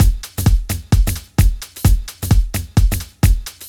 Downtown House
Drum Loops 130bpm